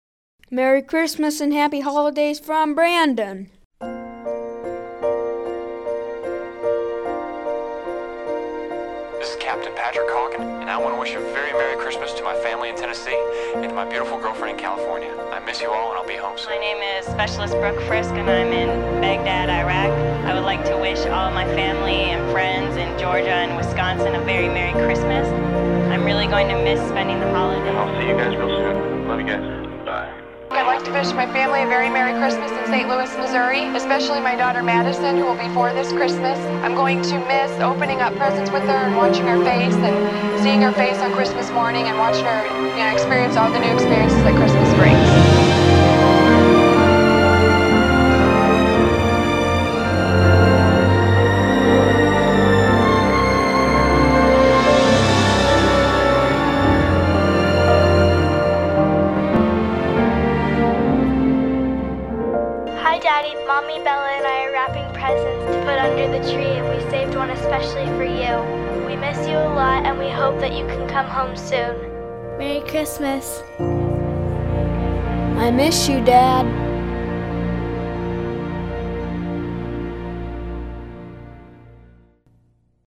Voice Overs (or Announcements) are an important part of the Belardo Lights Display. They provide information to folks watching the display, such as our website address, hours of operation, how many lights we have, etc. In addition, we try to use the announcements to inject a little humor into the display to make it fun for all of our visitors.
The voice overs heard on Belardo Lights are played through the system speakers, as well as broadcast on the FM airwaves locally on FM 106.1 for visitors driving by the display.